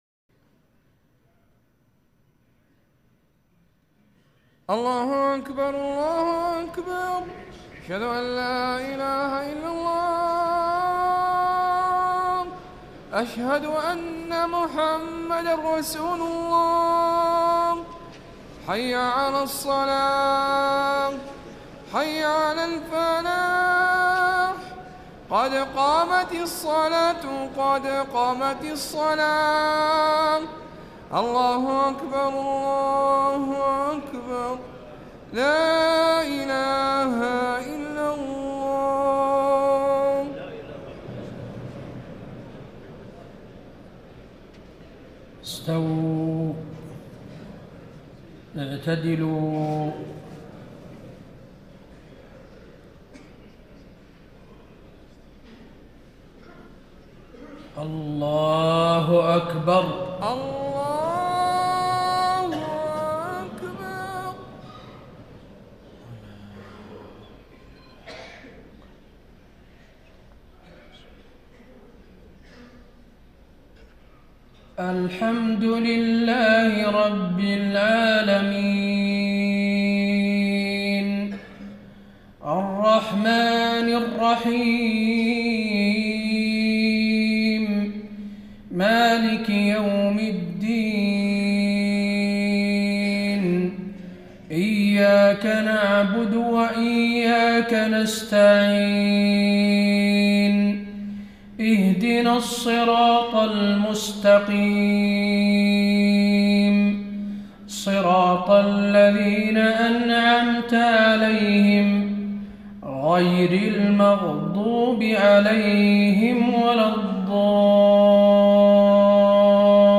صلاة المغرب 9-4-1435هـ سورتي العصر و الإخلاص > 1435 🕌 > الفروض - تلاوات الحرمين